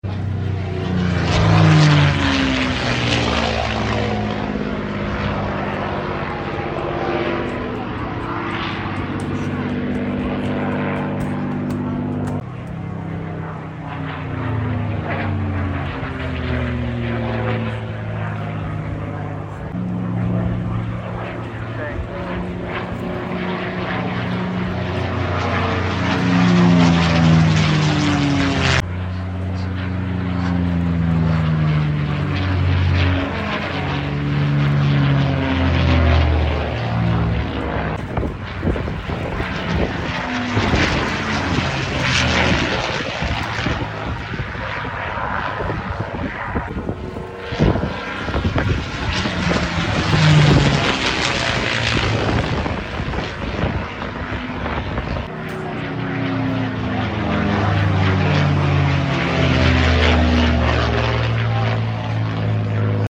P51 “Moonbeam McSwine” display at